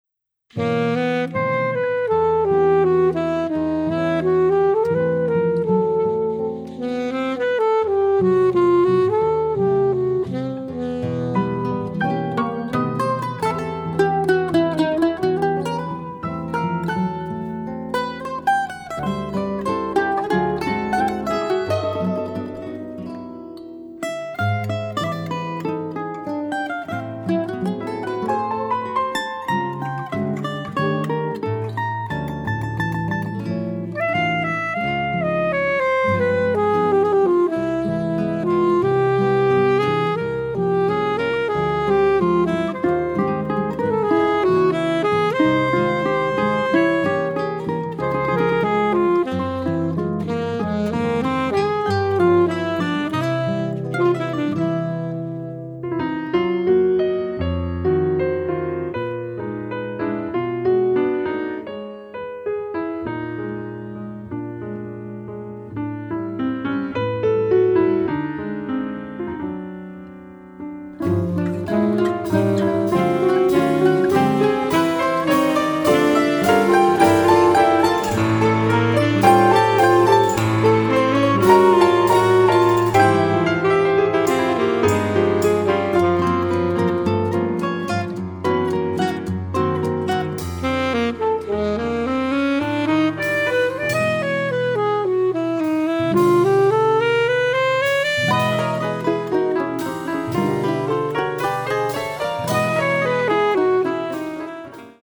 全体を貫く浮遊感も心地良い大充実の1枚です！